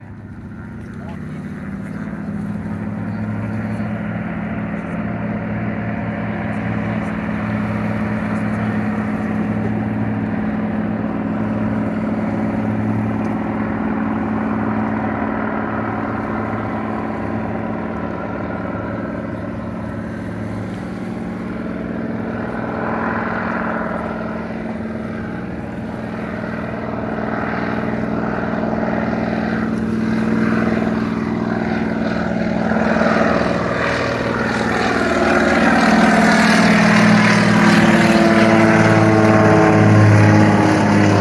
De Havilland Canada DHC-3 Seaplane Acoustics
It is powered by a Pratt & Whitney R-1340 Wasp, 600 hp, air cooled, radial engine with a propeller hub speed of 2250 rpm (37.5 Hz).
I took the video of the takeoff using an Android Smartphone, then extracted the audio track and calculated the waterfall FFT.
The spectral peaks experience frequency shifts due to the Doppler effect. The 107 Hz spectral peak is due to the blade passing frequency.
seaplane.mp3